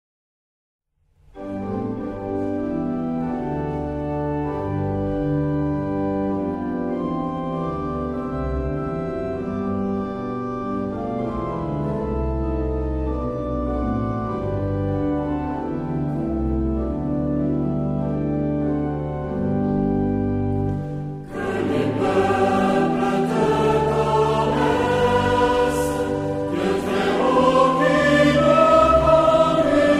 Genre-Style-Forme : Sacré ; Cantique
Caractère de la pièce : joyeux
Type de choeur : SATB OU unisson  (4 voix mixtes )
Instruments : Orgue (1)
Tonalité : sol majeur